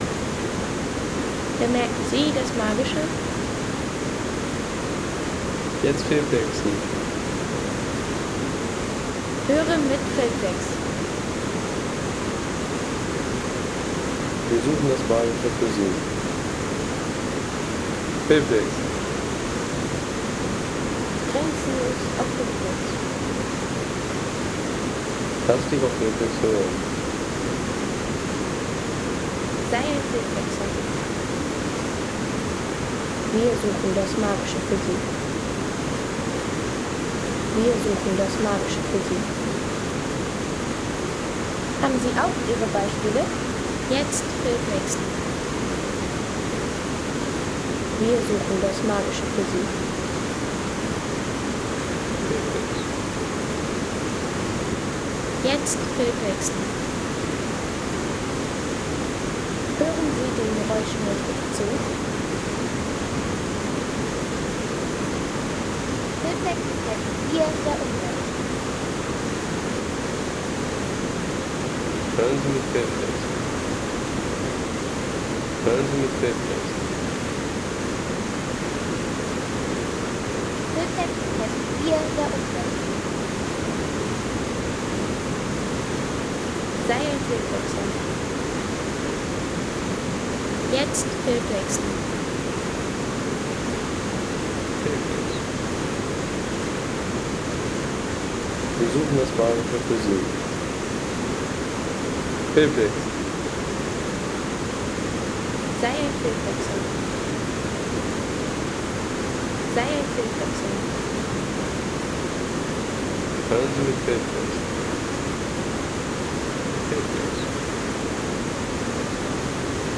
Ötztaler Ache Home Sounds Landschaft Flüsse Ötztaler Ache Seien Sie der Erste, der dieses Produkt bewertet Artikelnummer: 163 Kategorien: Landschaft - Flüsse Ötztaler Ache Lade Sound.... Wildwasser der Ötztaler Ache bei der Wellerbrücke – Gewaltige Str 3,50 € Inkl. 19% MwSt.